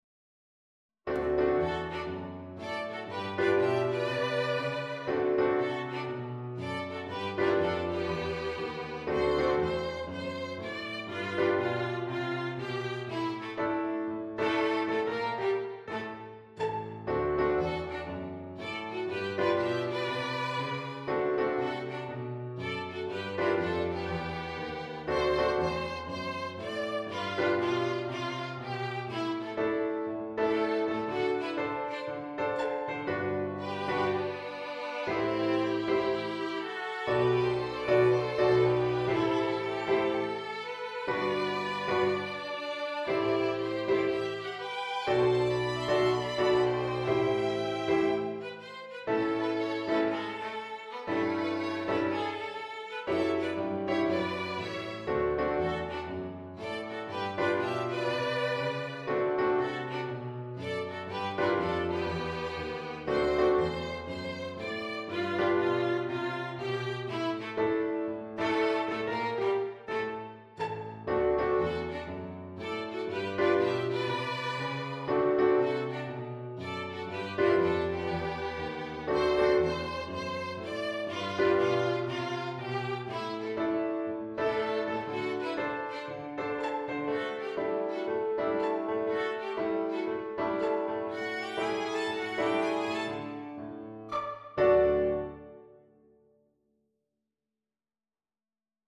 Two Violins and Piano